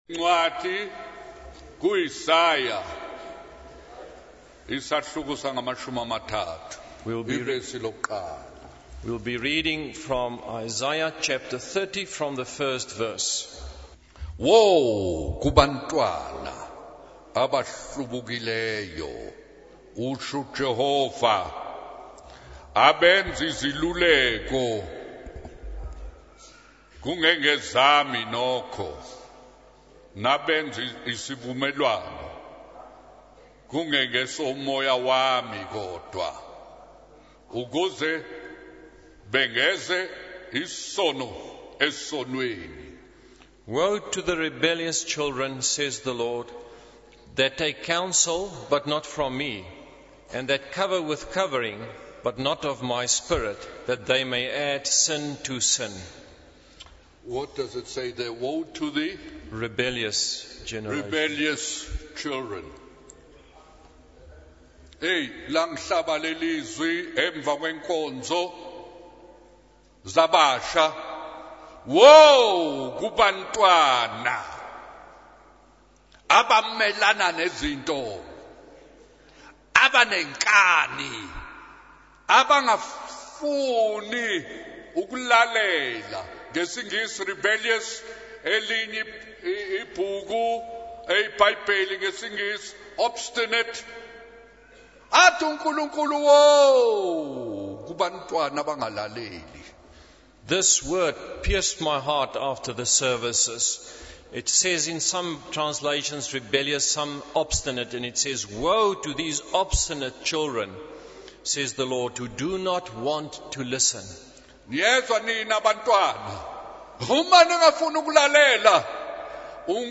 The speaker emphasizes the importance of surrendering one's life to the Lord and not hiding from Him. They also highlight the idea that sin is like a chain, with one sin leading to another. The sermon references the story of Adam and Eve hiding from God and the words of Jesus in John 3:20 about those who do evil hating the light.